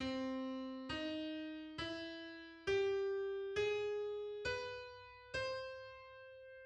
English: Augmented scale on C.
Augmented_scale_on_C.mid.mp3